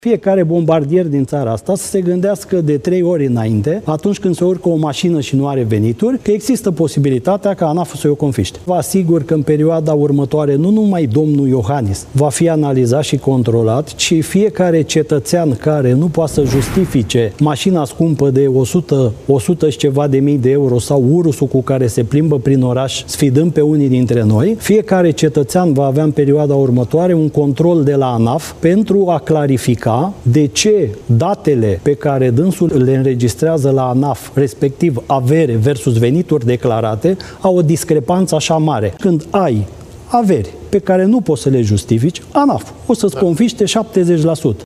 Șeful ANAF, Adrian Nica, avertizează că toți cei care dețin mașini de lux și nu pot justifica cu ce bani au fost achiziționate riscă să rămână fără aceste autoturisme. Și, în cazul averii nejustificate, va fi confiscată 70 la sută din sumă, a avertizat președintele ANAF, Adrian Nica, la Antena 3.